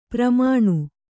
♪ pramāṇu